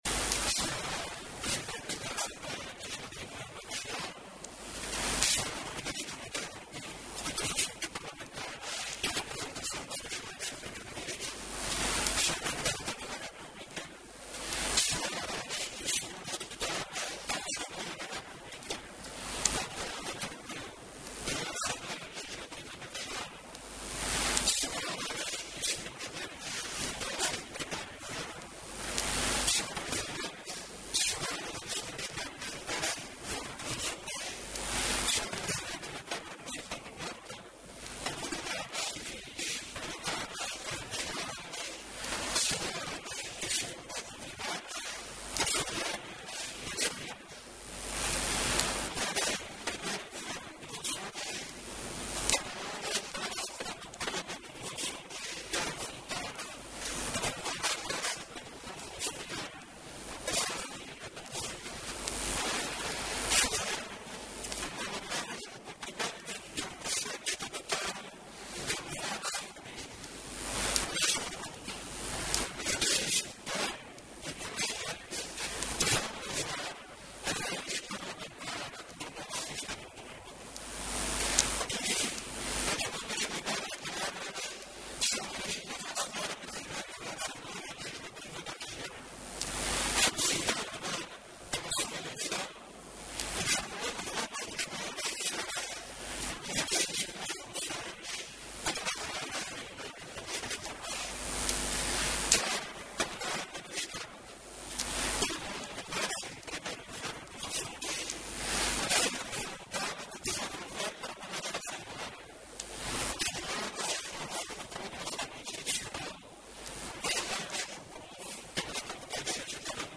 Notícias dos Açores: Intervenção do Presidente do Governo na Sessão Solene da Tomada de Posse do XI Governo dos Açores